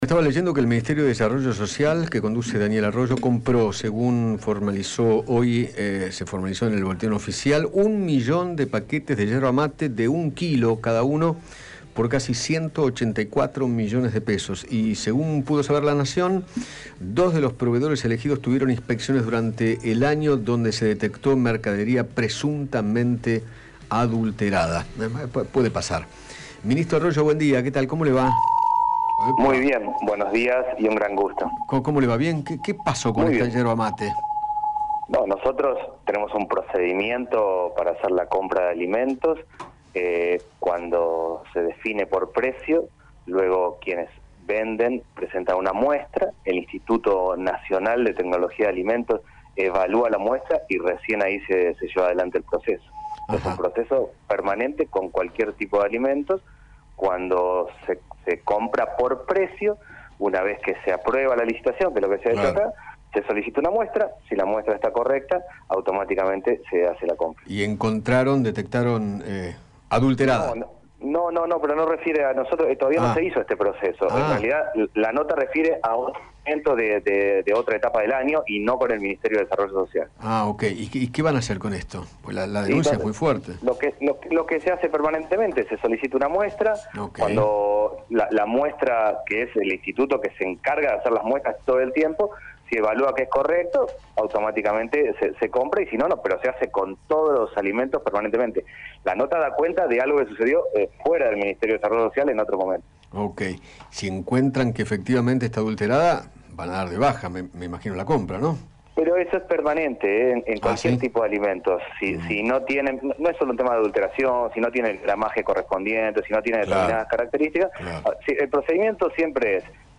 Daniel Arroyo, Ministro de Desarrollo Social, dialogó con Eduardo Feinmann sobre la asistencia del Gobierno durante la pandemia, el nivel de indigencia, y se refirió a la situación alimentaria.